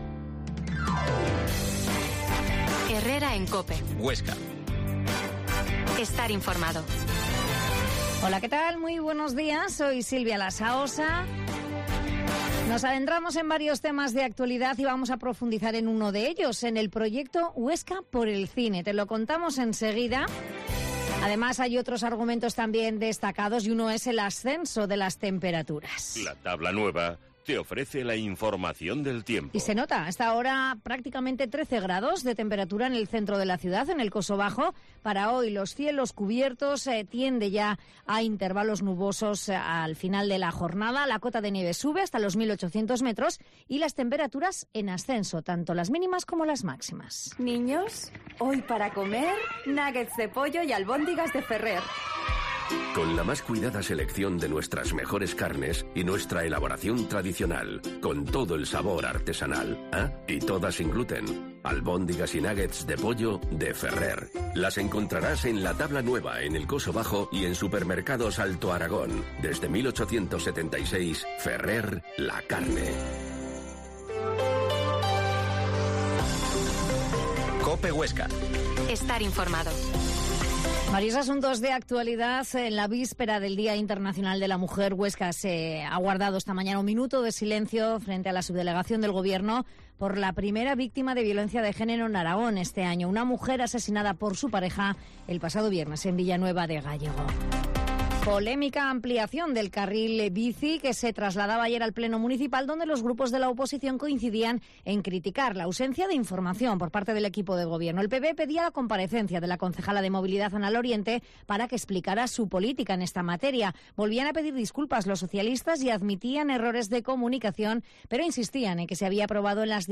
Herrera en COPE Huesca 12.50h Entrevista a la concejal de desarrollo, Rosa Gerbás